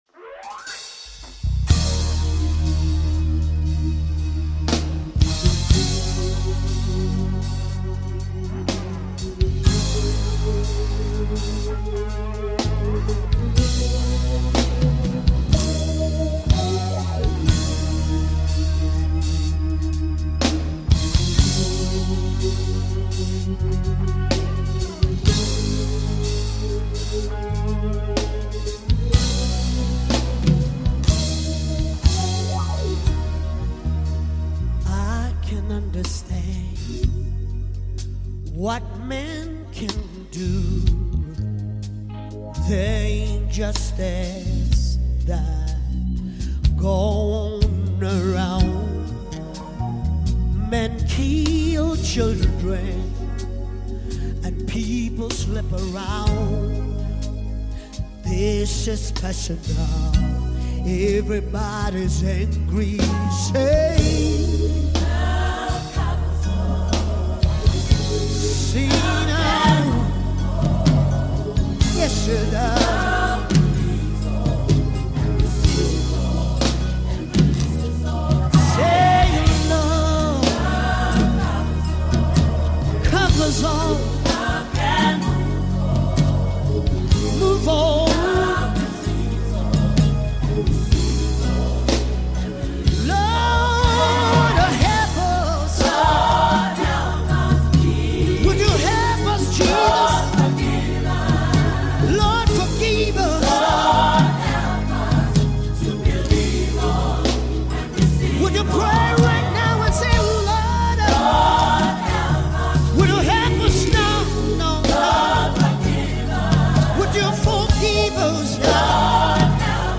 February 21, 2025 Publisher 01 Gospel 0